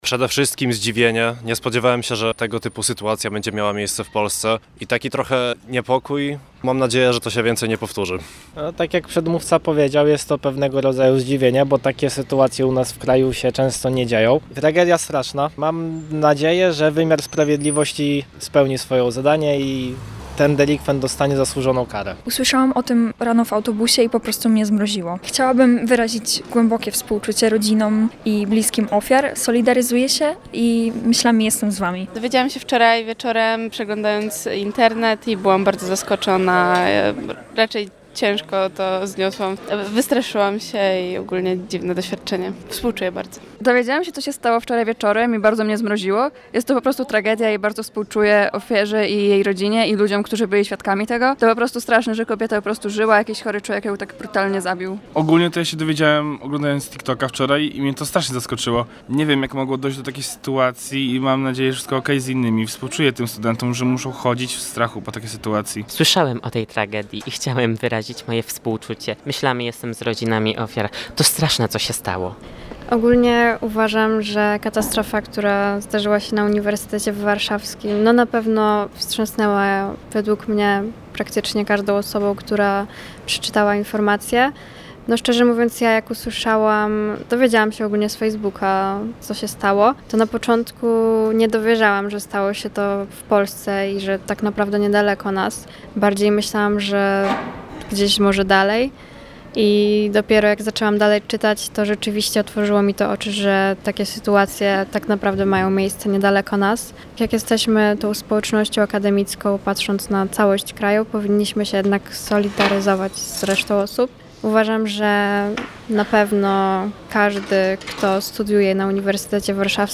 0805-MA-studenci-o-morderstwie-na-UW.mp3